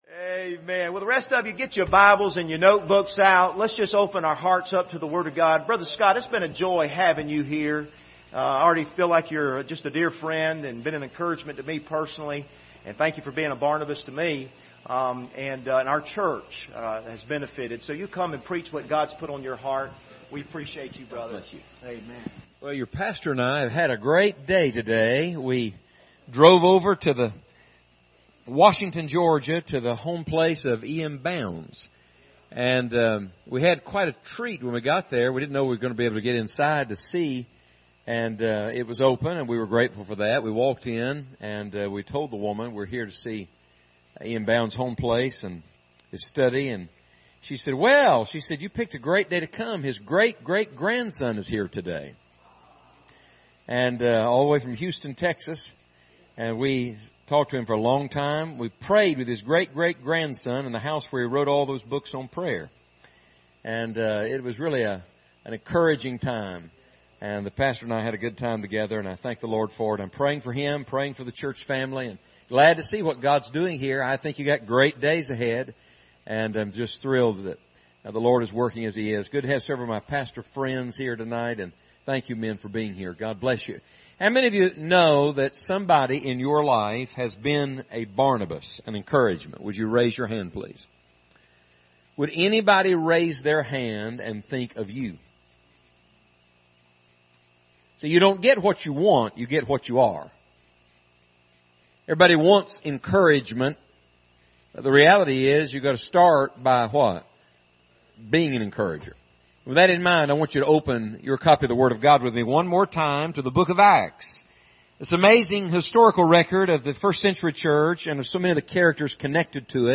Service Type: Revival Service Topics: perseverance